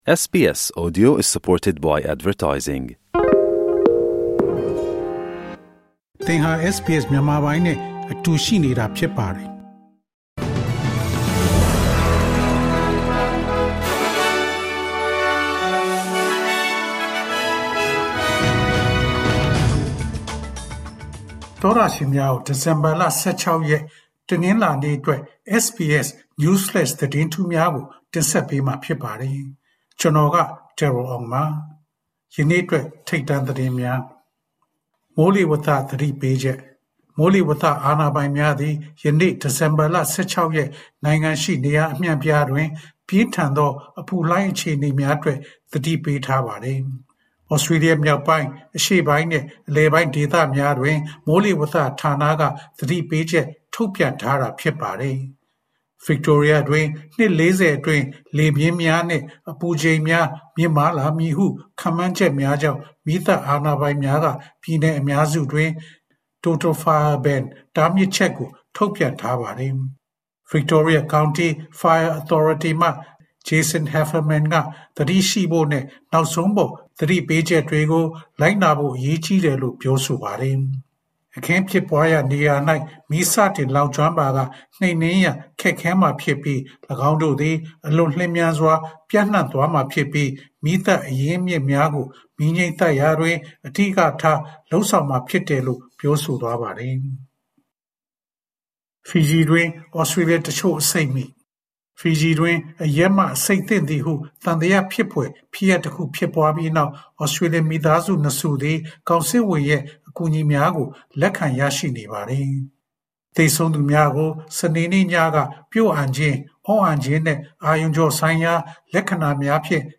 SBS မြန်မာ ၂၀၂၄ နှစ် ဒီဇင်ဘာလ ၁၆ ရက် News Flash သတင်းများ။